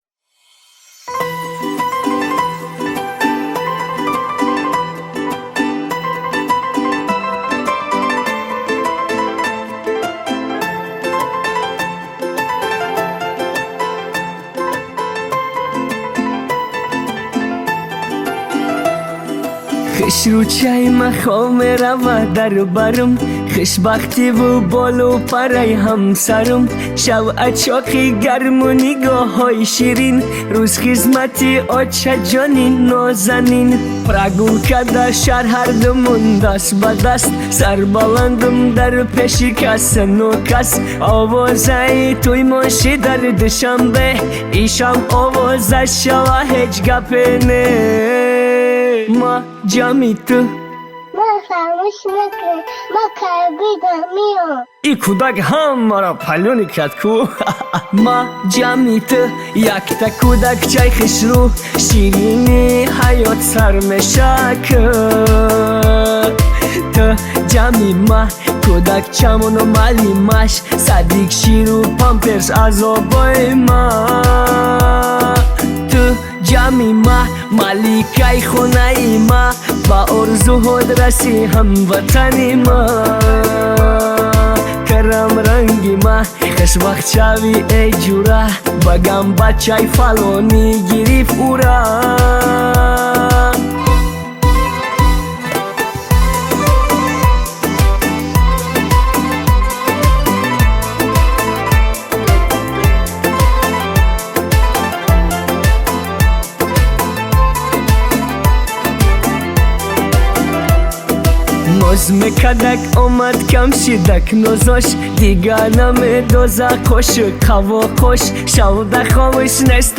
Точик музика
Из художественного фильма